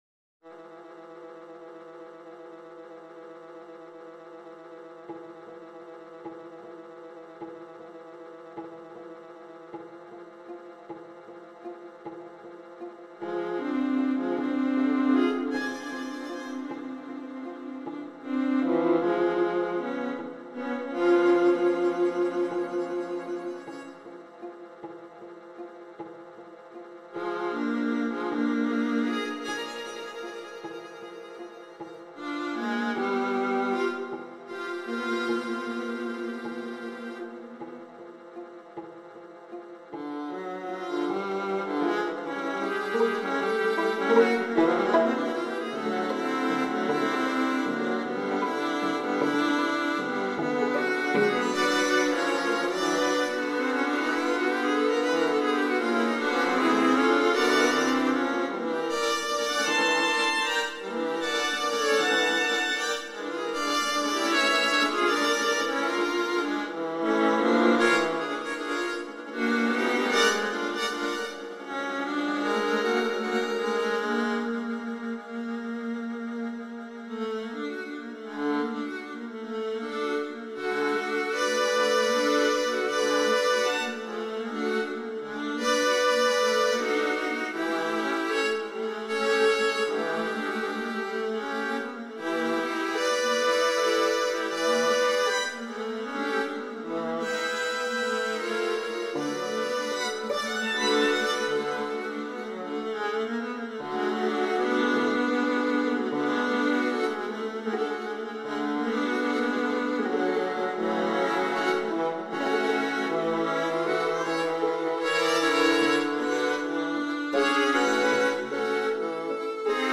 O but what a sound! 13 mins A